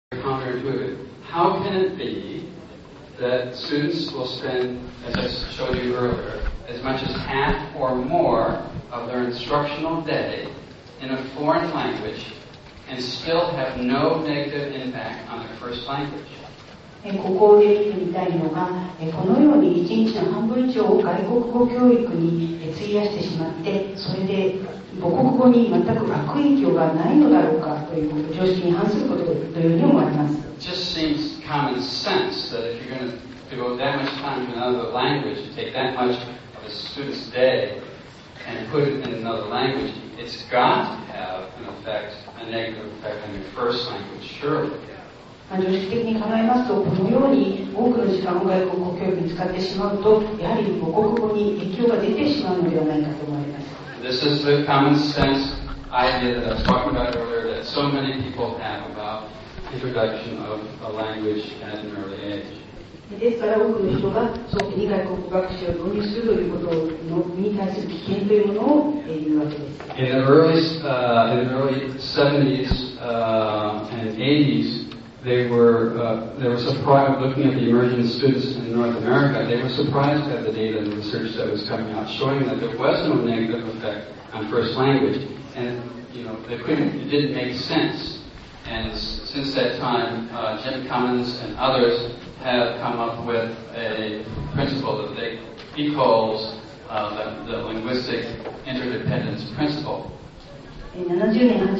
Photos & Sound Clips from the JALT 2005 Pan-SIG Conference
Plenary Clip 4